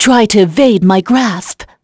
Veronica-Vox_Skill4.wav